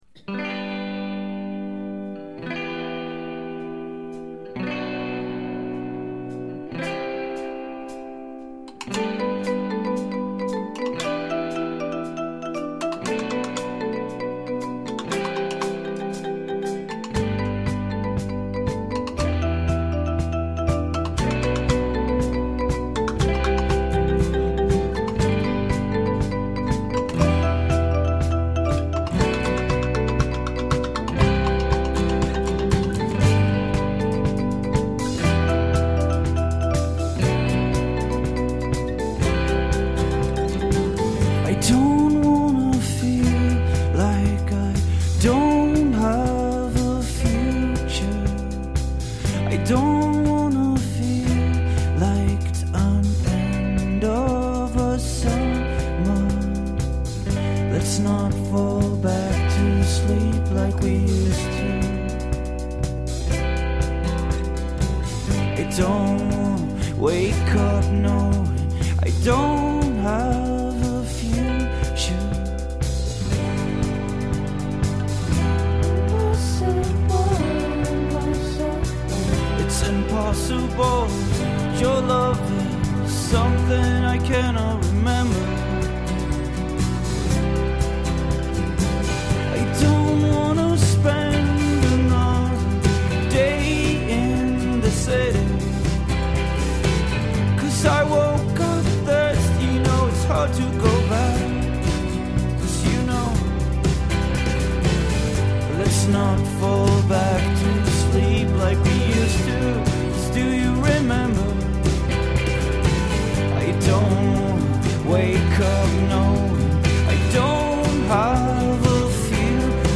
(acoustic)